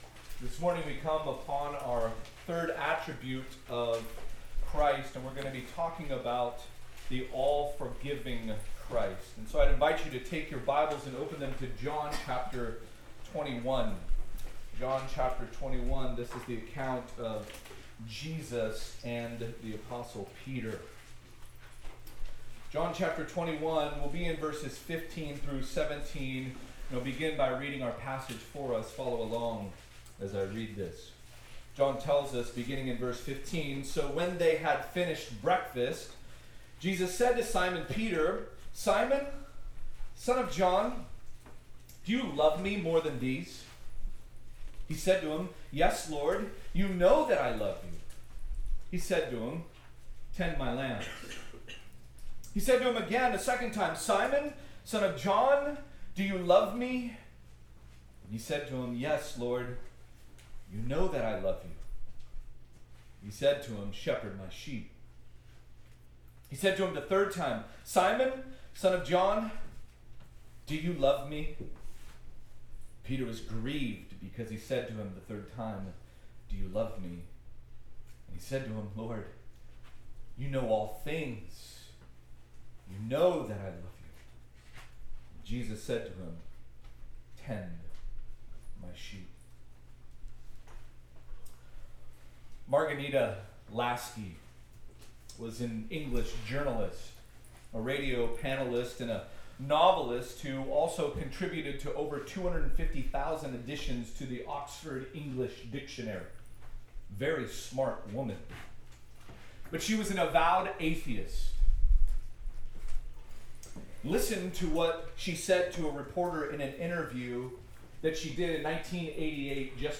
College/Roots Roots Summer Retreat 2025 - On the Shores of Galilee Audio ◀ Prev Series List Previous 2.